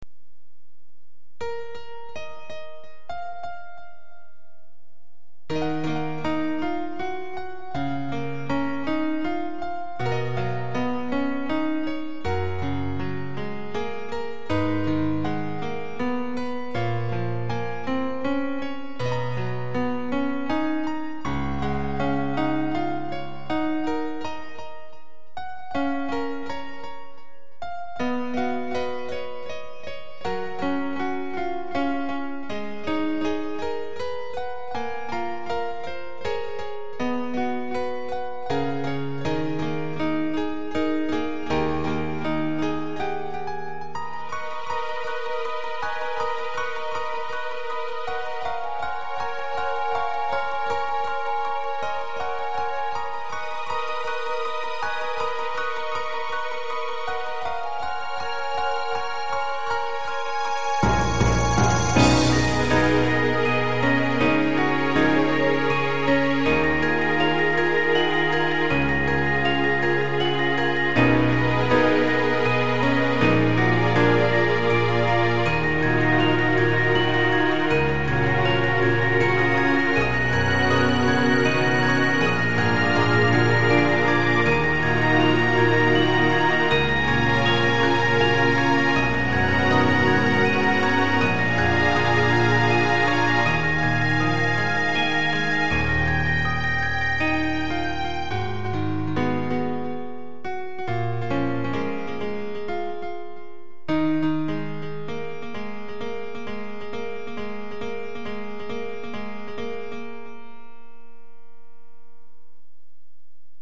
インストゥルメンタル